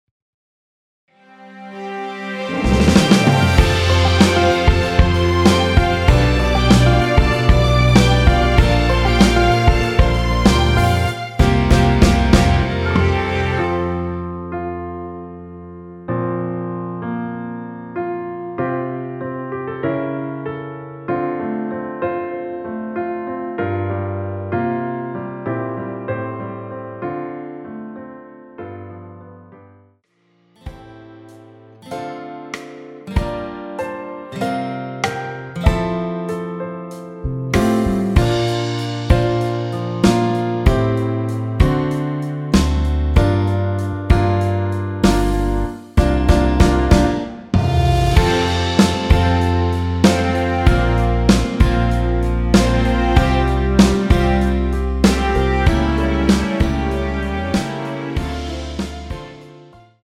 원키에서(+3)올린 (1절앞+후렴)으로 진행되는 MR입니다.(본문 가사 참조)
앞부분30초, 뒷부분30초씩 편집해서 올려 드리고 있습니다.